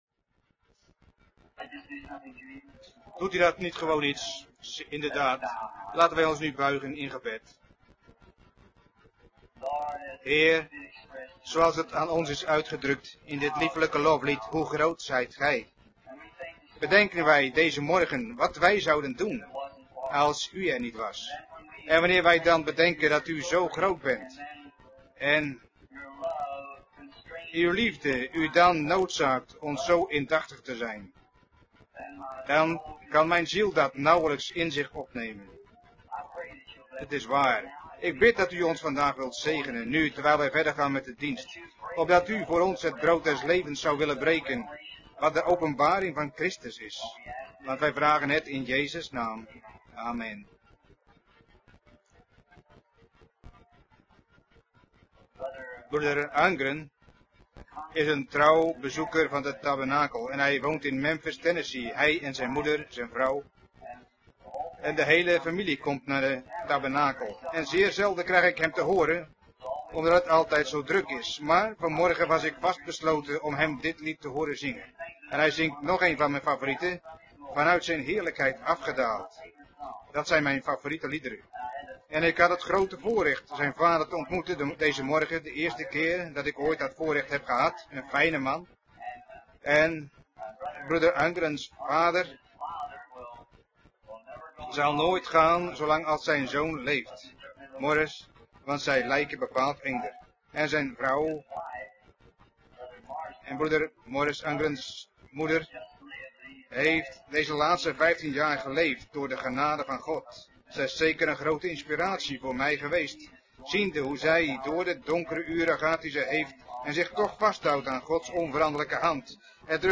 Vertaalde prediking "An absolute" door William Marrion Branham te Life Tabernacle, Shreveport, Louisiana, USA, 's ochtends op zondag 01 december 1963